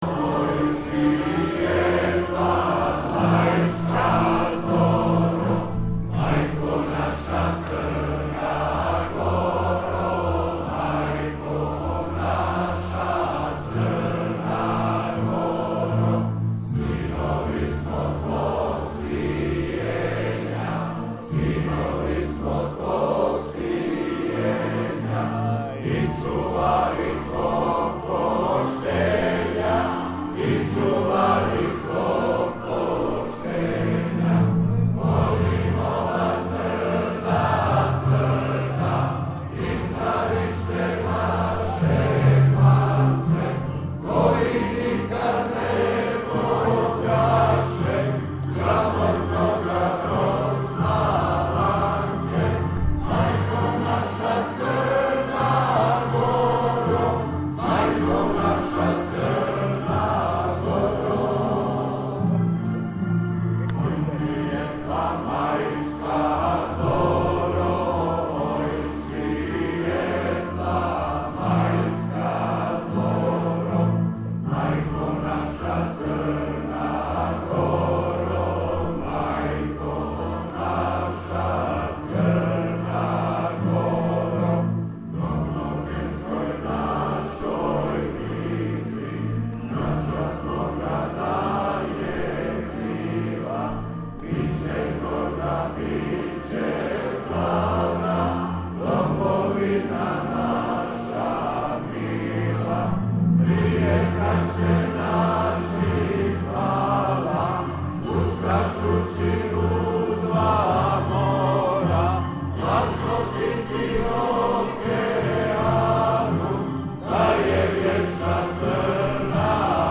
skup.mp3